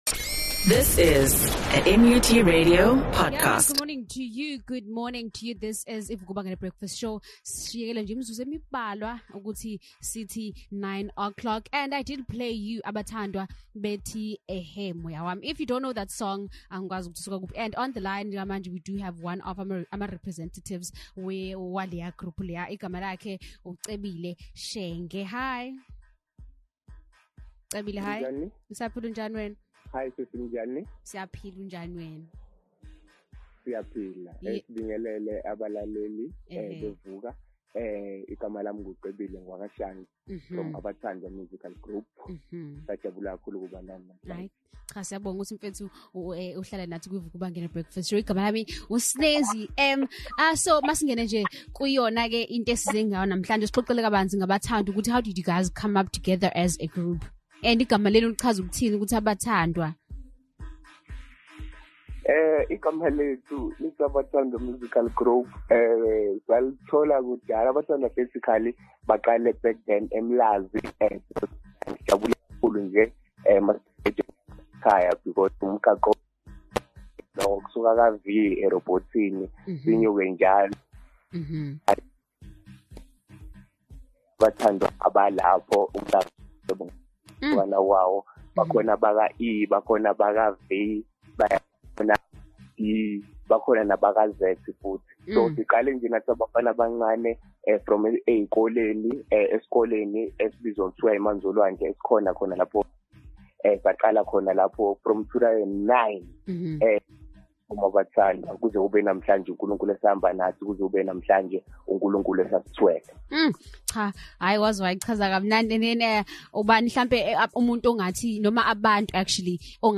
interview
VUK'UBANGENE BREAKFAST SHOW ABATHANDWA musical group , gospel singers